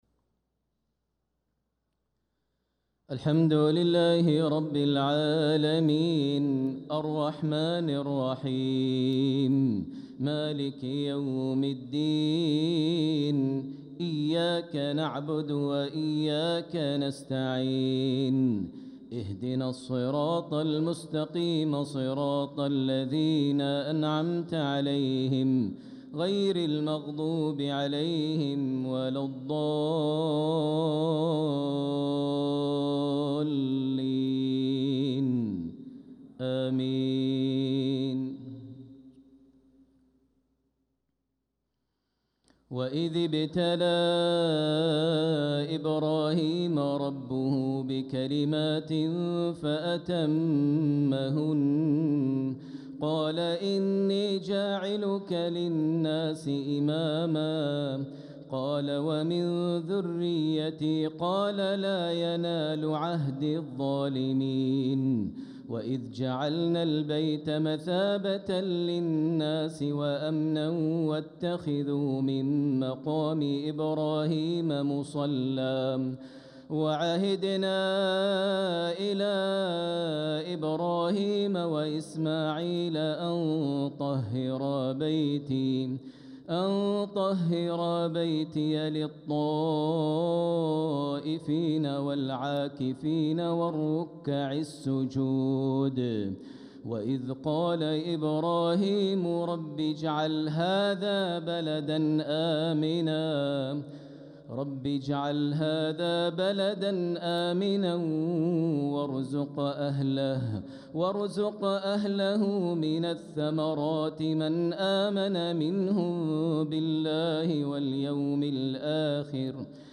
صلاة العشاء للقارئ ماهر المعيقلي 21 ربيع الأول 1446 هـ
تِلَاوَات الْحَرَمَيْن .